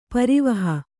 ♪ parivaha